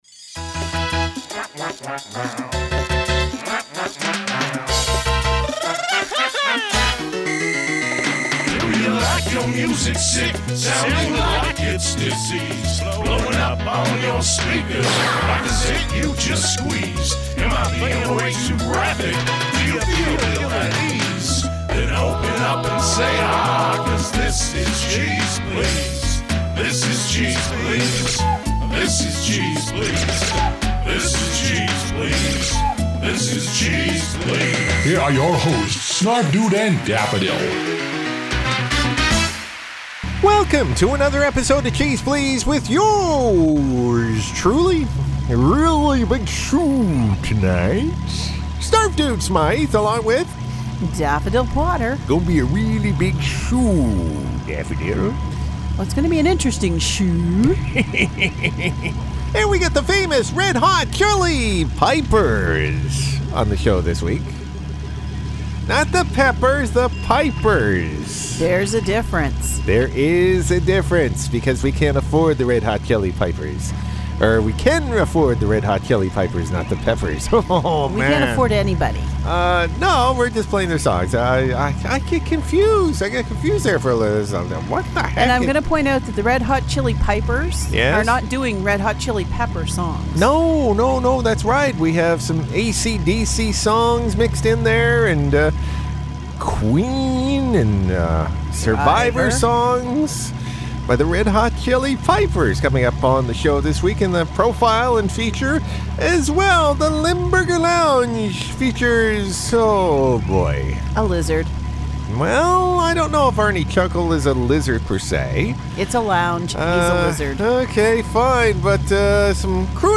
Crankin out the covers with a bagpipe feel.